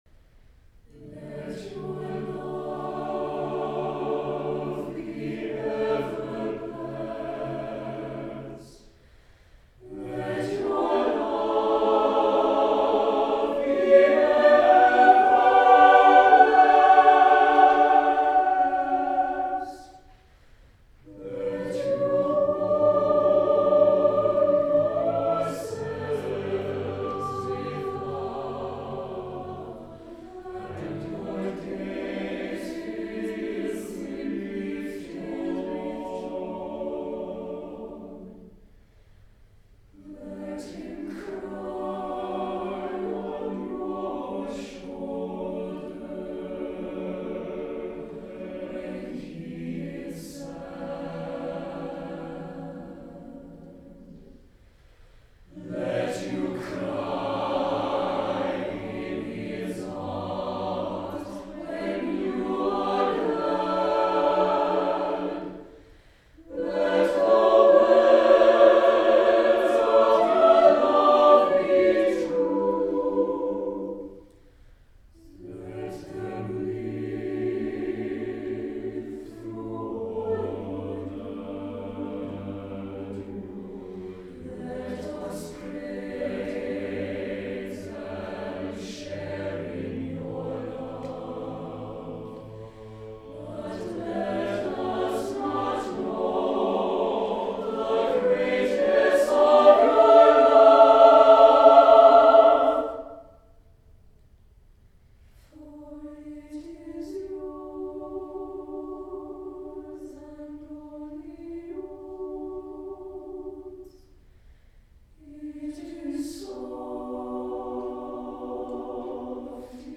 for SATB chorus a cappella